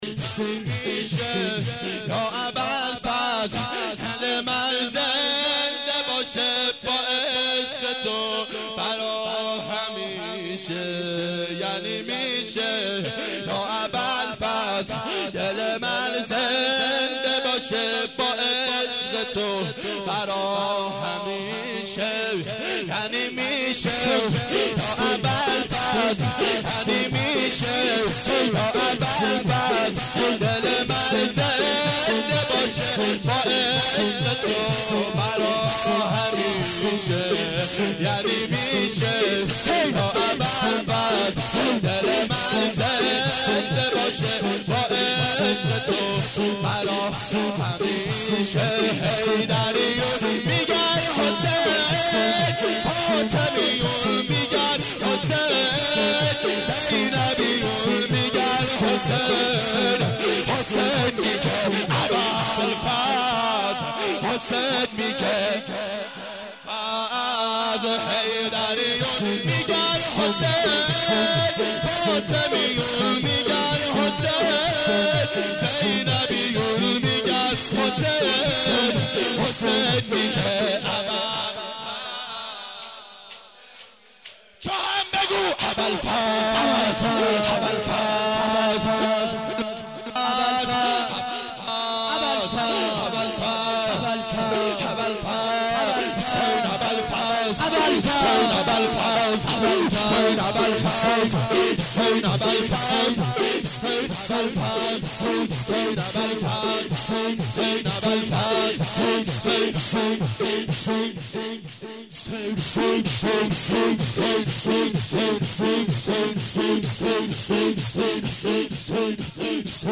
گلچین جلسات هفتگی سال 1387